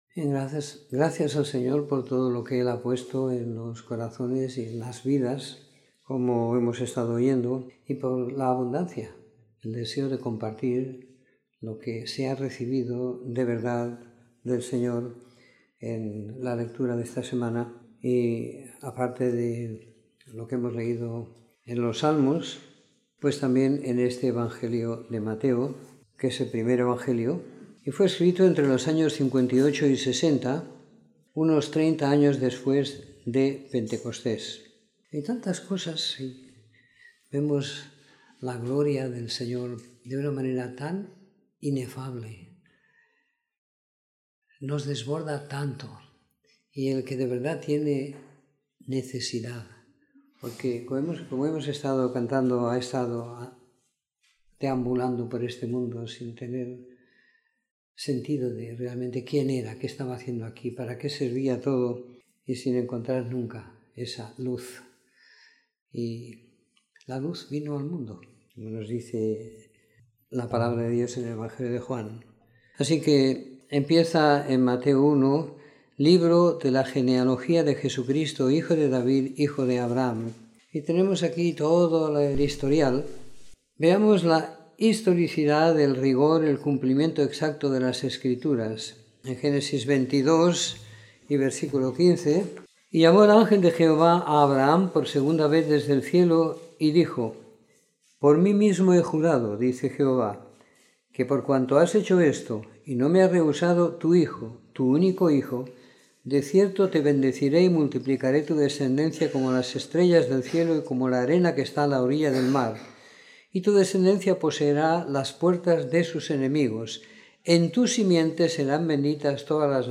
Comentario en Mateo 1-13 - 12 de Enero de 2018
Comentario en el libro de Mateo del capítulo 1 al 13 siguiendo la lectura programada para cada semana del año que tenemos en la congregación en Sant Pere de Ribes.